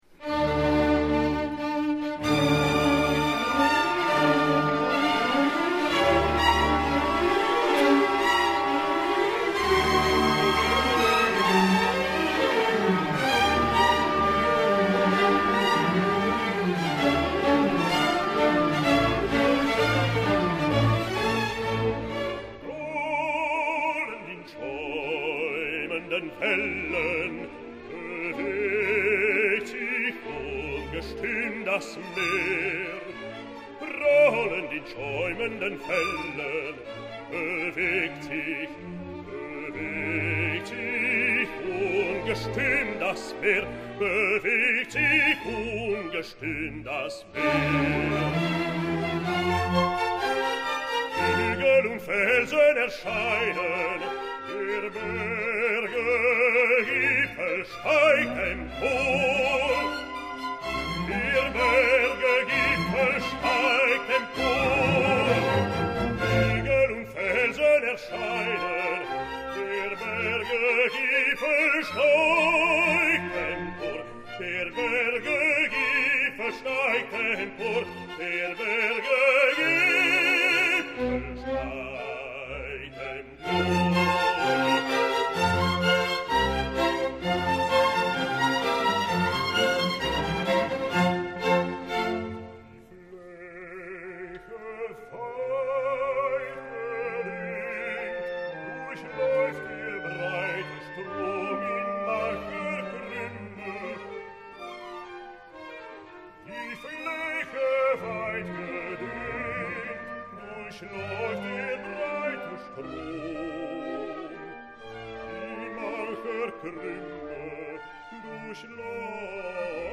오라토리오 <천지창조> Die Schopfung (The Creation)
소프라노와 테너, 그리고 베이스 솔리스트가 세 천사를 맡아서 아름다운 아리아들을 노래한다.
Herbert Von Karajan(Conductor),  Berlin Philharmoniker,  독창자, 합창단 미파악
영창(라파엘) - 거친 물결 일어나서